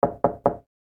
Samsung Galaxy Bildirim Sesleri - Dijital Eşik
Knock
knock.mp3